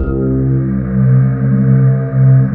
Index of /90_sSampleCDs/USB Soundscan vol.28 - Choir Acoustic & Synth [AKAI] 1CD/Partition D/07-STRATIS